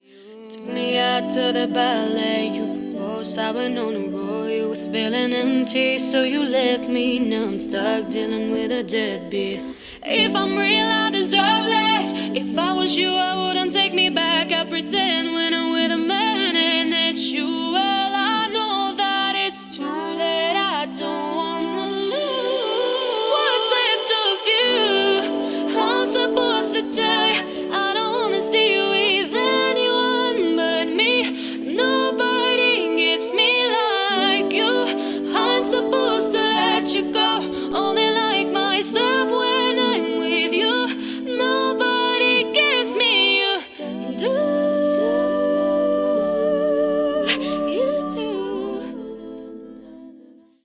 Как вокал?)